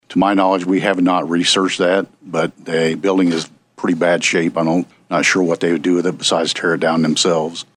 One committee member asked Lamb if the State Engineer or the Corrections Department had researched the possibility of selling the building rather than demolishing it, to which Lamb replied….